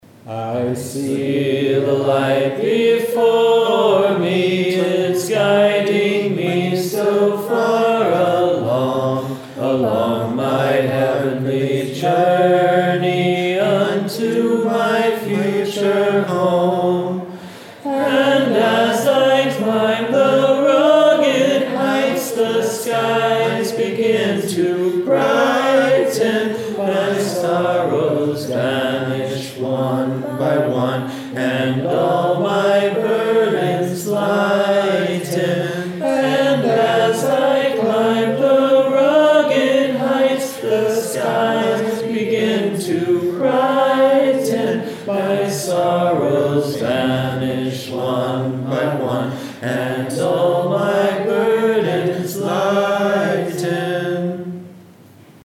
Each week, members of the choral study group assemble to learn a thematically related archival piece of vocal music by ear.
Princeton Shaker Rehearsal